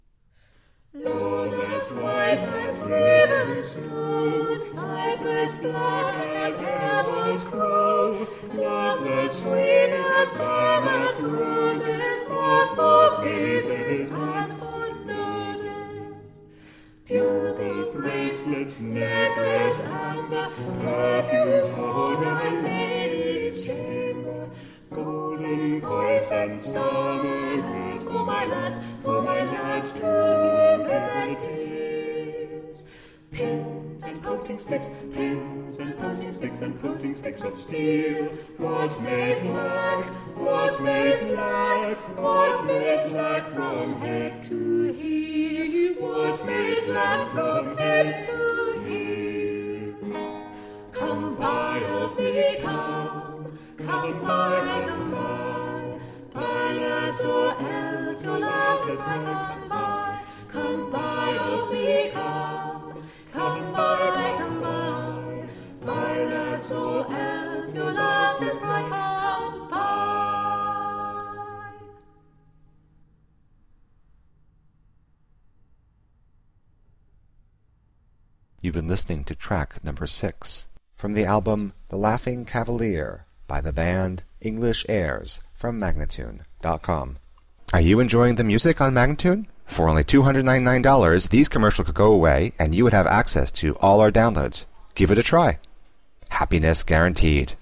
Traditional early english music.